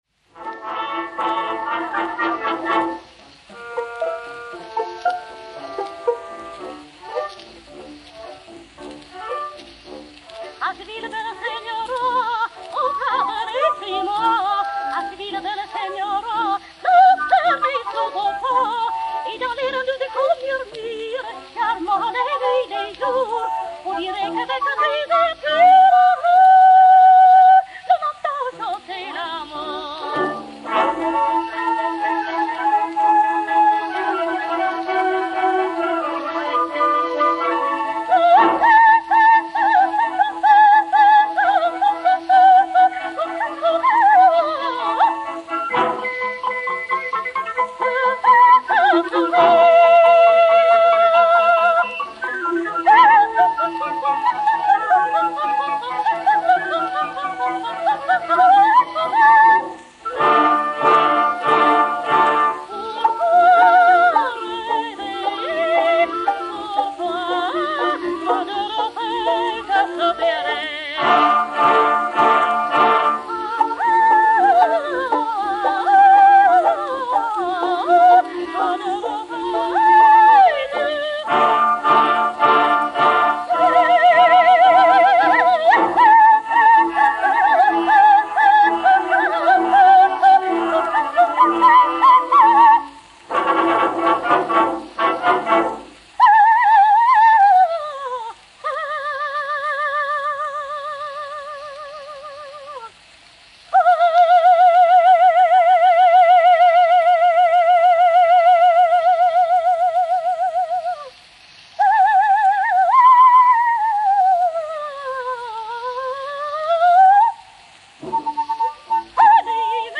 soprano, avec Orchestre
enr. à Londres le 11 mai 1910